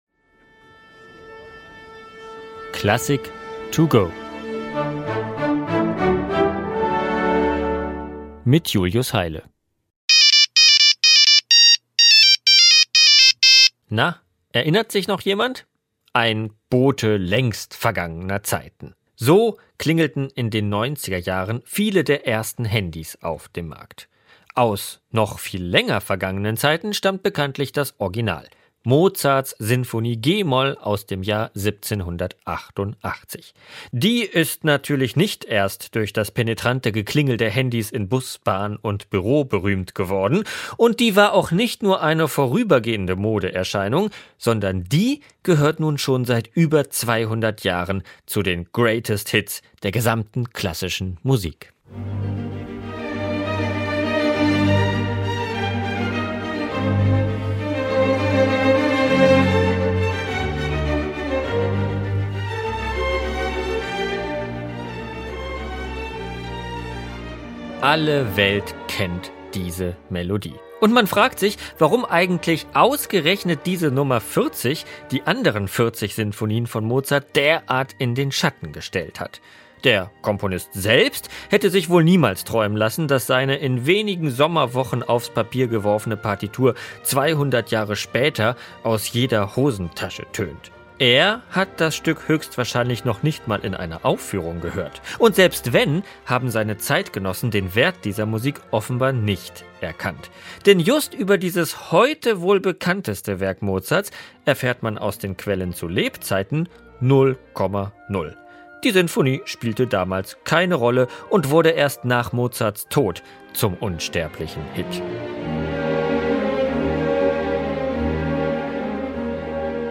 Kurzeinführung.